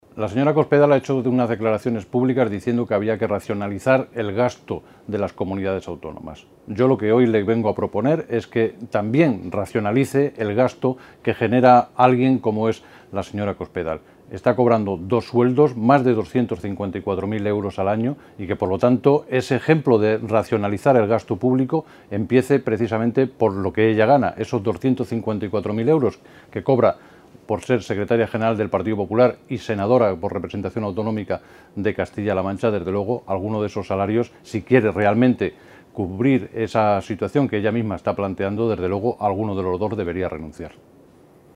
El senador socialista José Miguel Camacho ha criticado hoy que la dirigente del PP y senadora en representación de las Cortes de C-LM, María Dolores de Cospedal, haya empezado el año en la Cámara Alta “como lo terminó y como viene siendo habitual en ella: haciendo novillos”.
Cortes de audio de la rueda de prensa